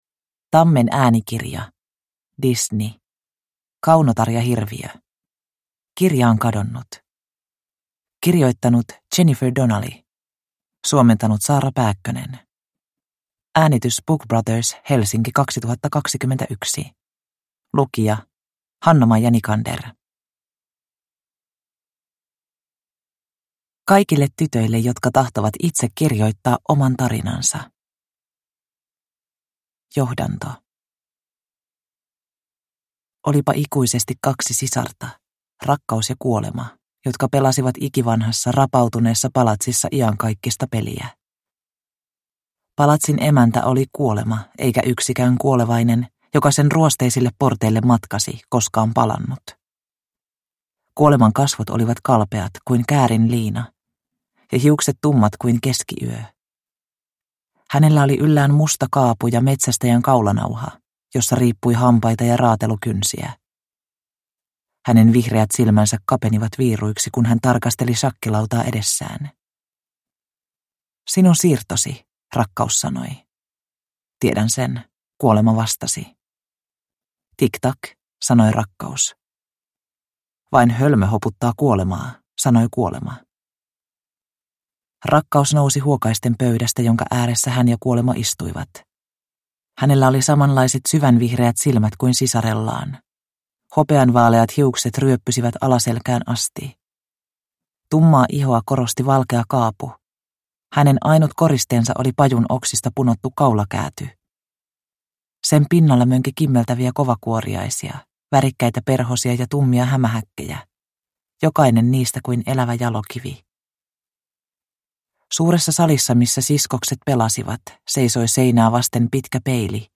Kirjaan kadonnut – Ljudbok – Laddas ner